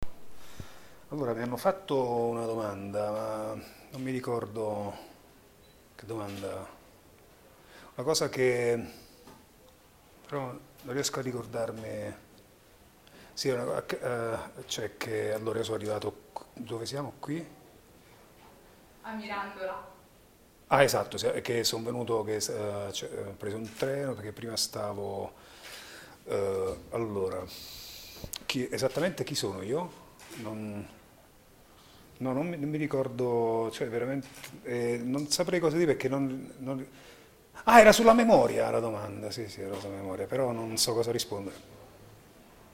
Lo stiamo scoprendo in questi giorni intervistando alcuni degli ospiti della seconda edizione del Memoria Festival di Mirandola: